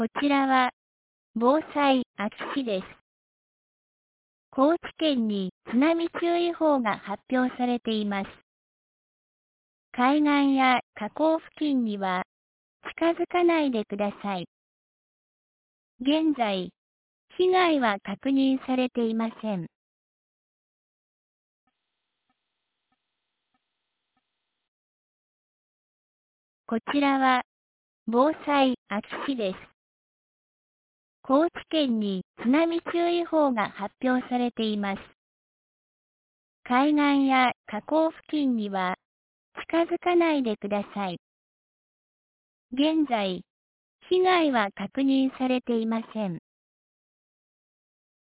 2023年10月09日 10時30分に、安芸市より全地区へ放送がありました。